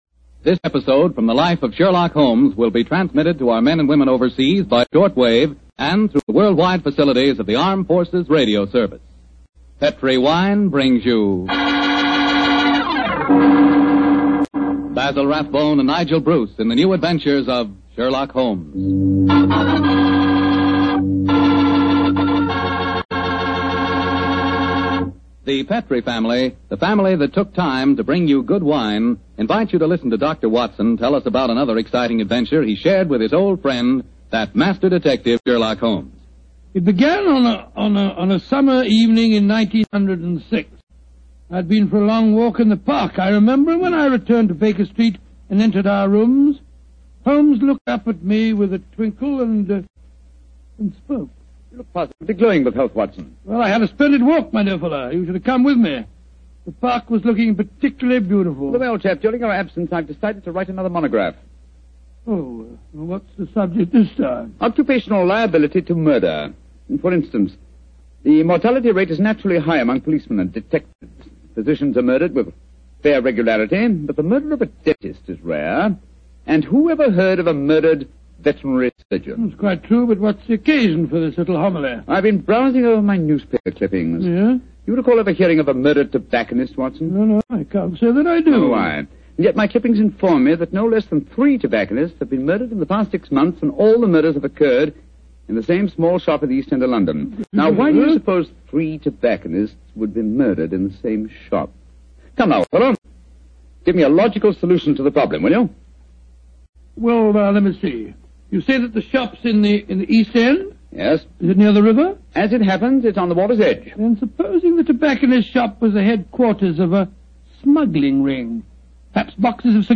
Radio Show Drama with Sherlock Holmes - The Unfortunate Tobacconist 1945